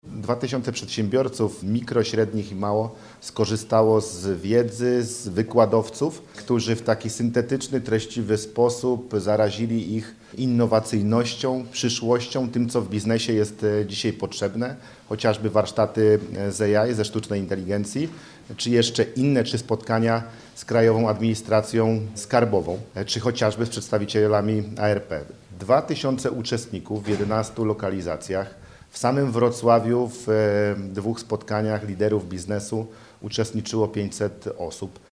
– Pierwsza edycja Dolnośląskich Liderów Biznesu objęła 11 spotkań w całym regionie o jedno więcej, niż pierwotnie planowano – mówi Paweł Gancarz, Marszałek Województwa Dolnośląskiego.